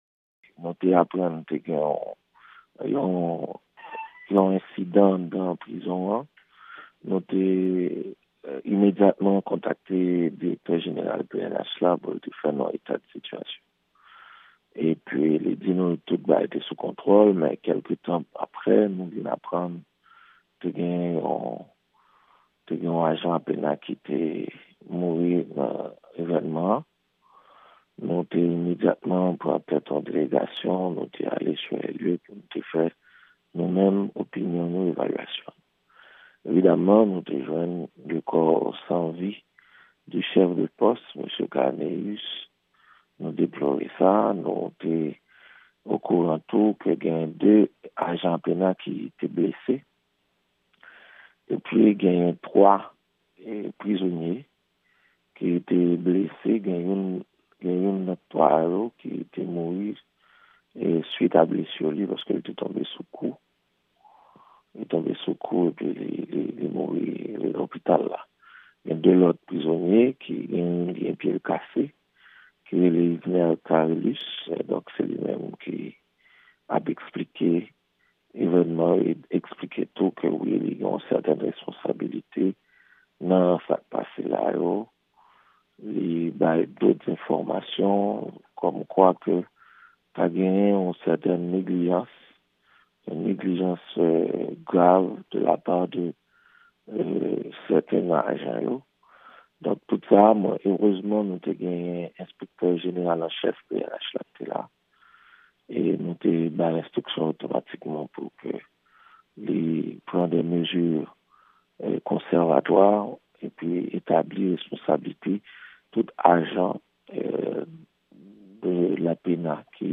Entèvyou Minis Jistis Ayisyen, Camille Edouard Junior, Bay Lavwadlamerik sou Dosye Prizonye ki Sove nan Prizon Akayè